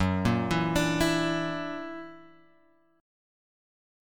F#+7 chord {2 1 2 x 3 0} chord